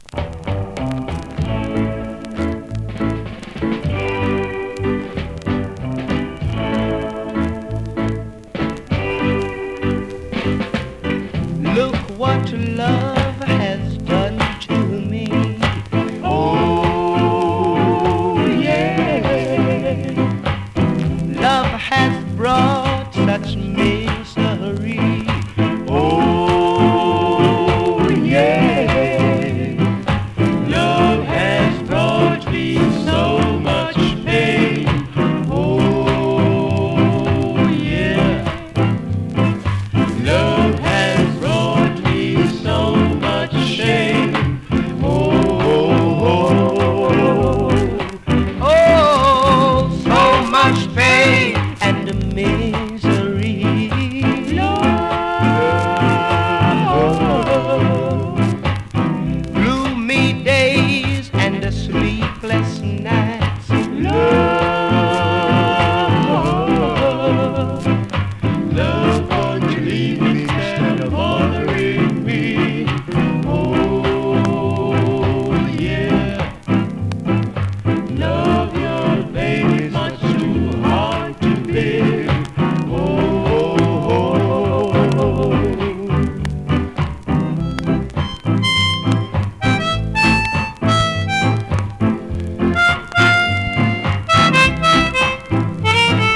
※チリチリ有